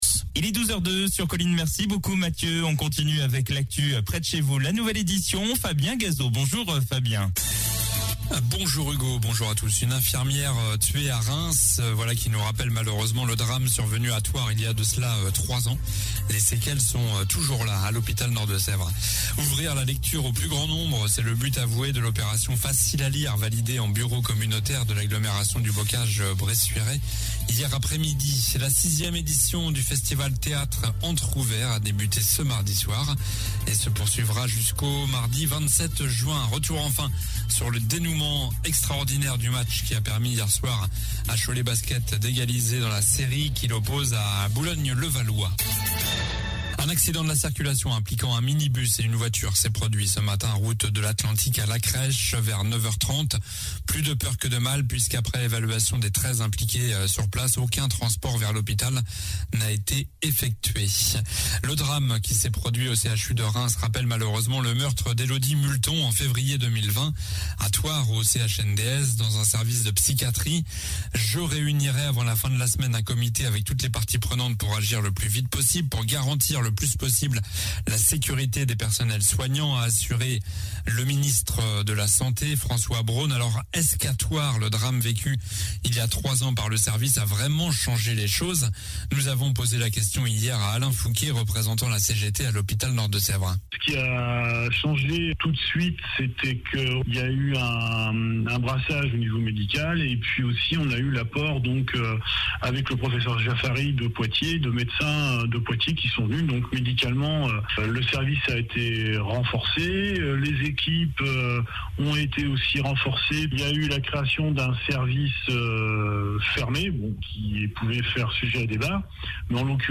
Journal du mercredi 24 mai (midi)